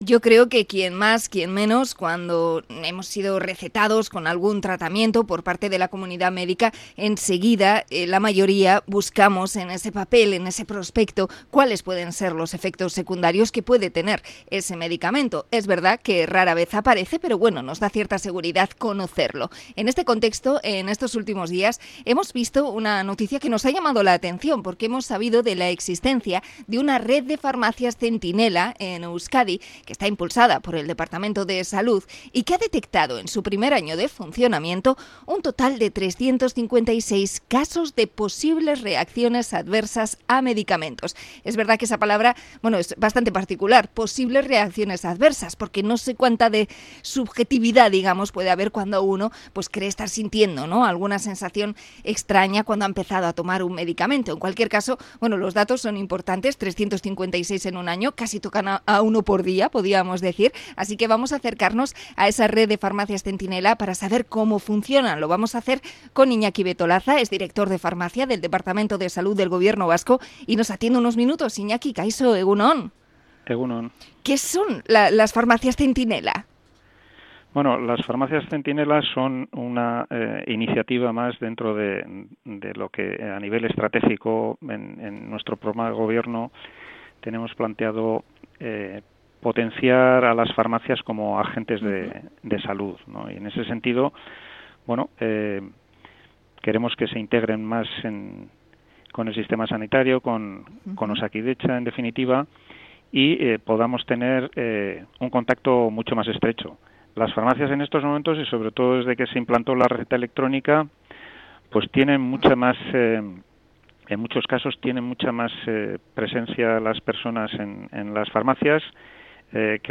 Entrevista con experto sobre la gestión de los efectos secundarios de medicamentos